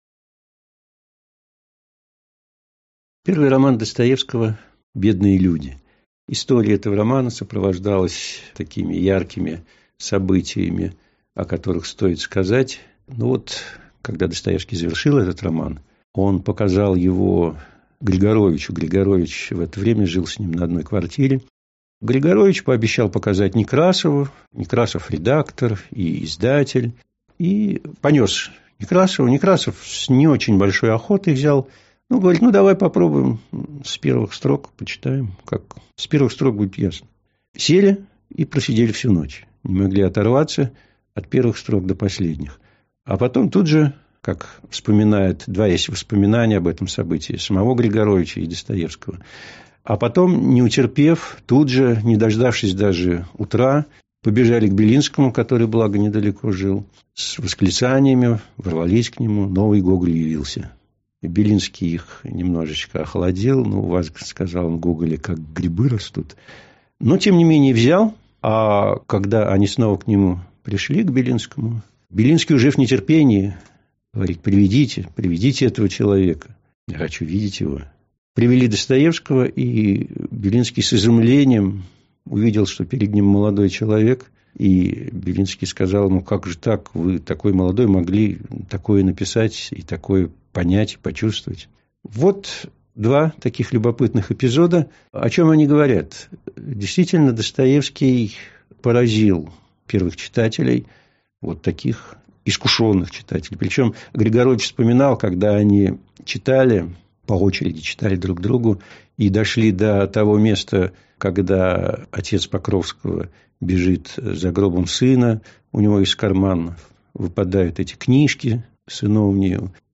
Аудиокнига «Бедные люди». Как «маленький человек» делается большим | Библиотека аудиокниг